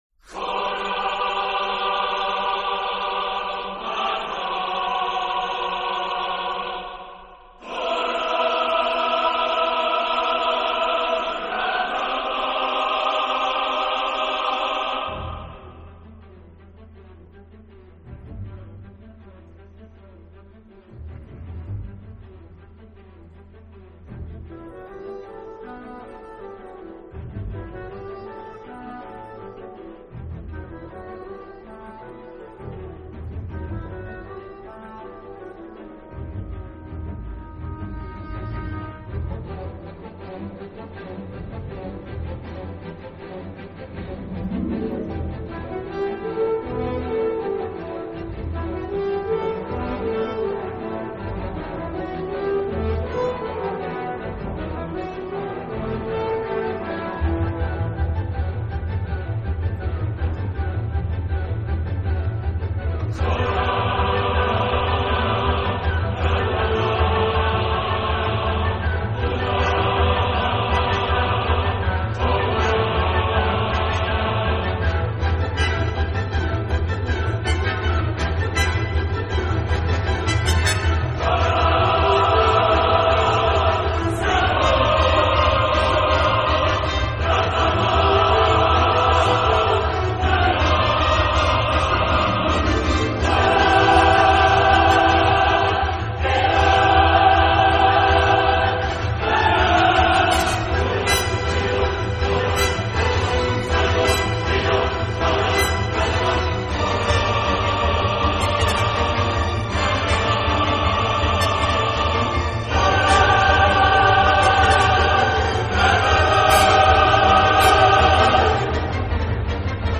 Soundtrack, Classical